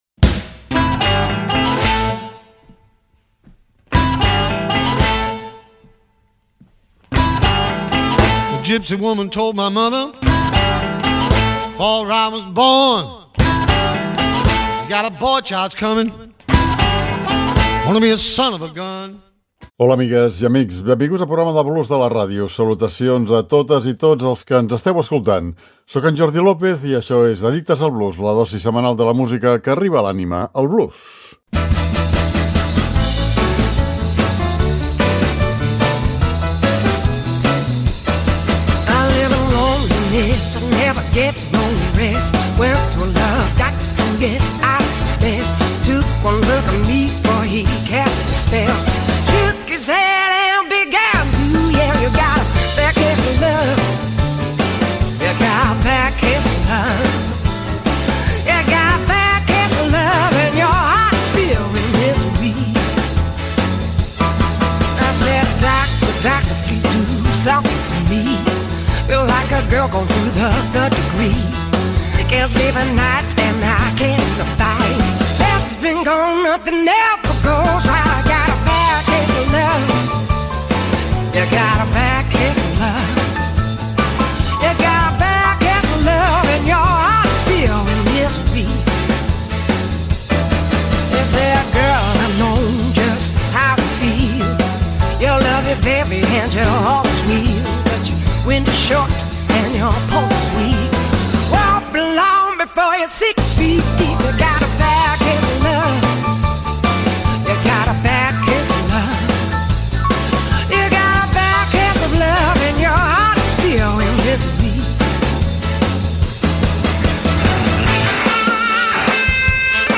guitarrista i cantant